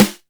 SC SNARE 1.wav